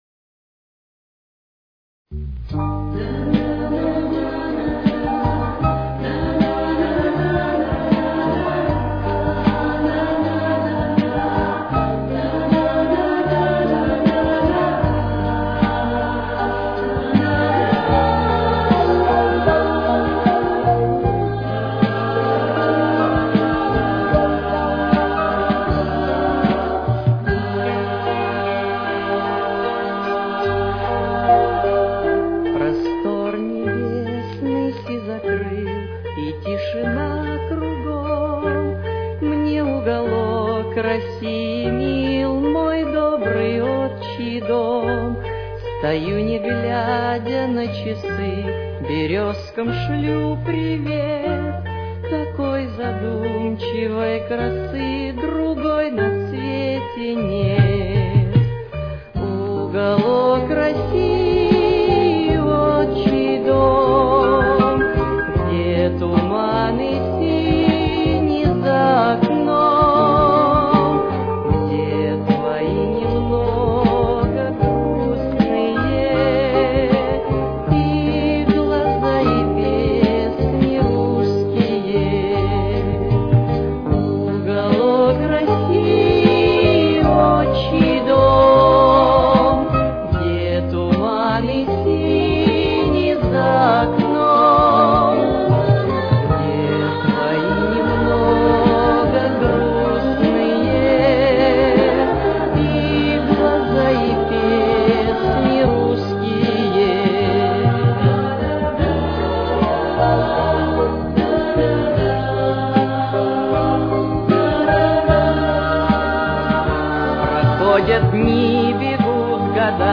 Тональность: Соль минор. Темп: 78.